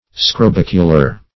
Search Result for " scrobicular" : The Collaborative International Dictionary of English v.0.48: Scrobicular \Scro*bic"u*lar\, a. (Zool.)